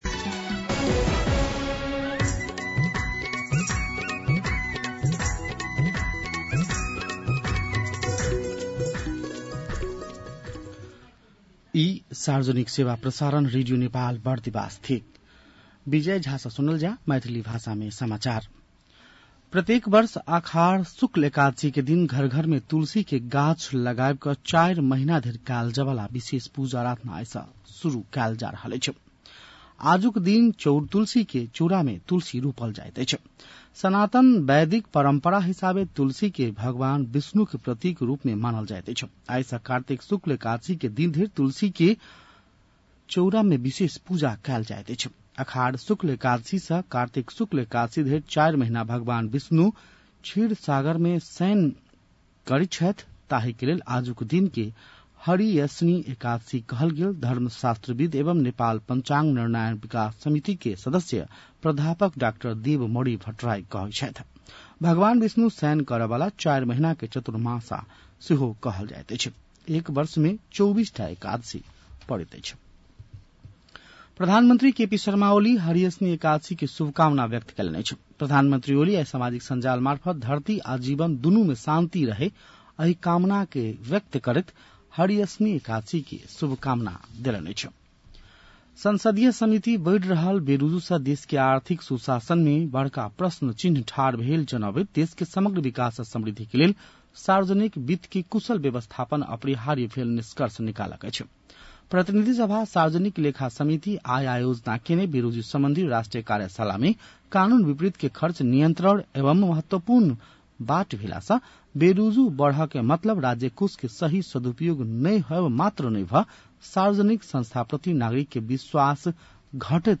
मैथिली भाषामा समाचार : २२ असार , २०८२
6.-pm-maithali-news-.mp3